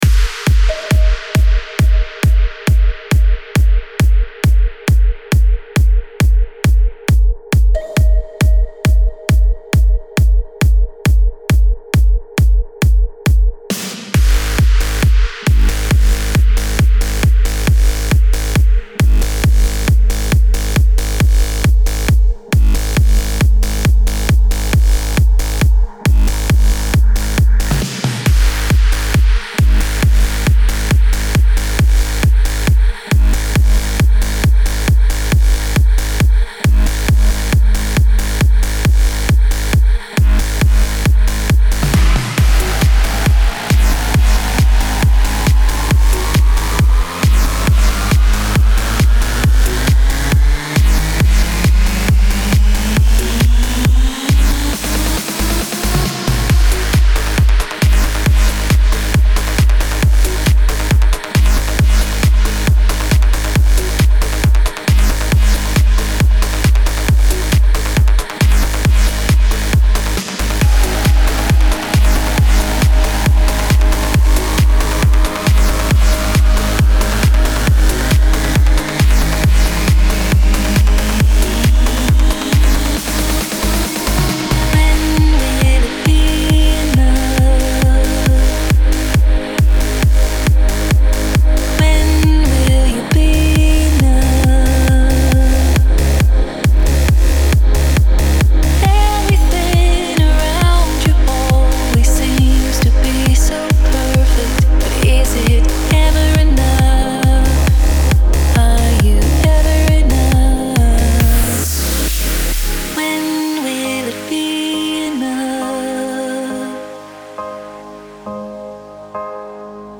Стиль: Trance / Uplifting Trance / Progressive Trance